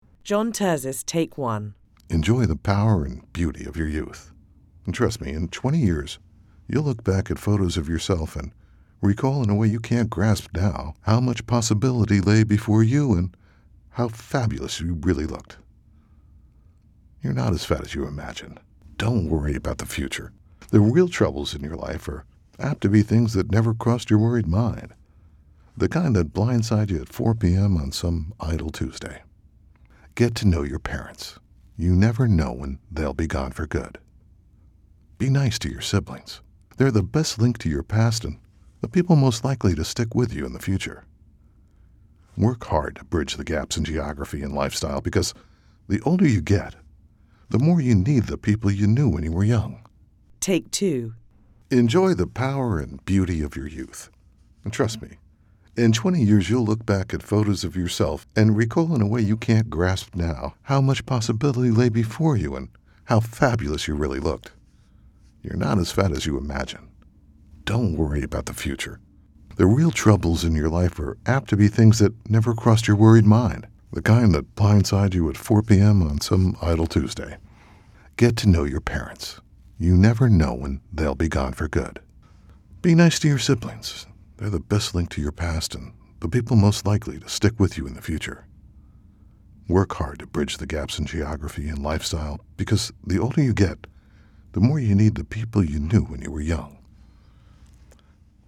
Confident, sophisticated, strong, commanding, conversational, sexy, cool, wry, serious or tongue and cheek.
Sprechprobe: Sonstiges (Muttersprache):
My full service, State of the Art studio is based in midtown Manhattan, your final recording will be produced at broadcast quality.